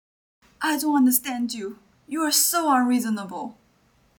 angry.m4a